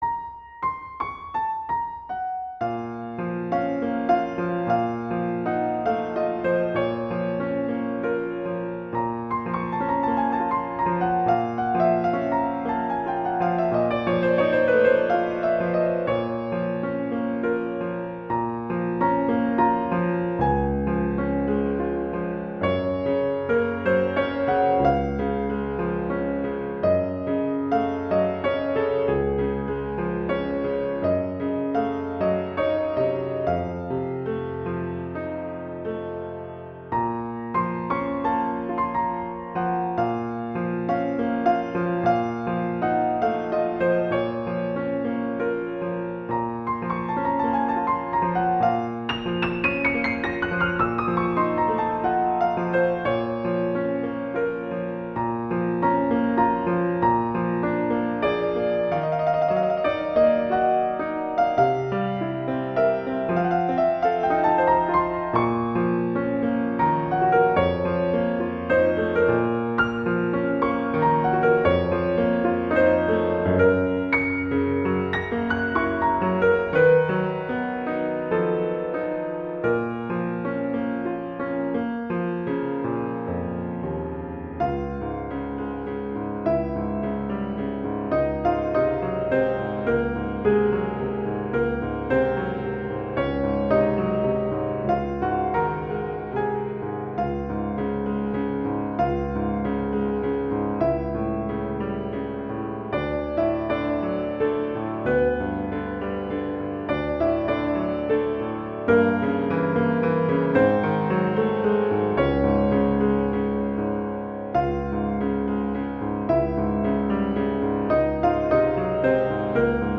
classical
♩=120 BPM